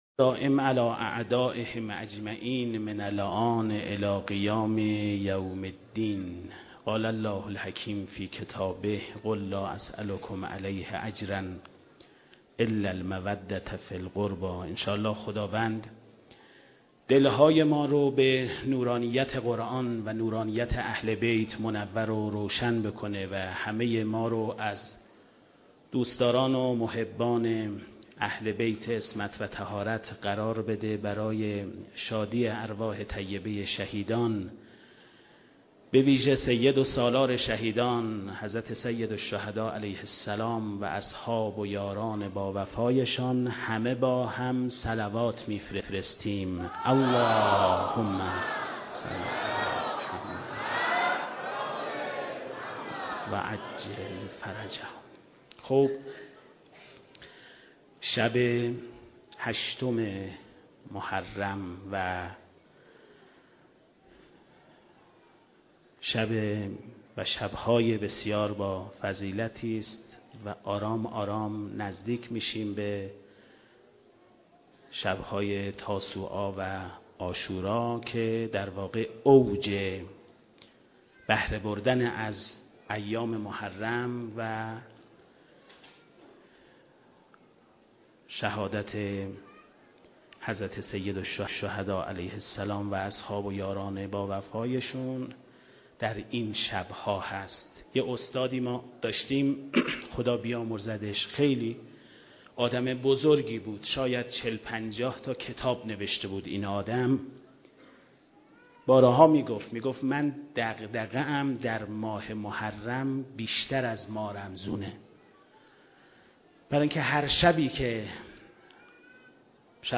صوت سخنرانی
وعظ و خطابه